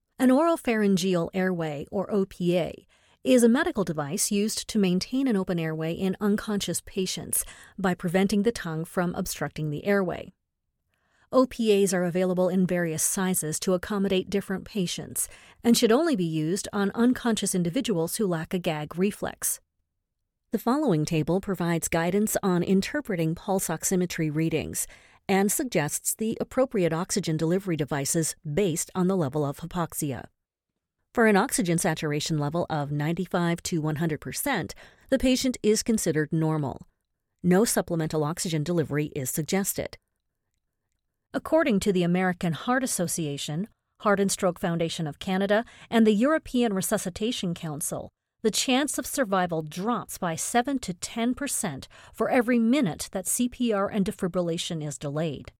English (Canadian)
Medical Narration
Neumann TLM 102 Microphone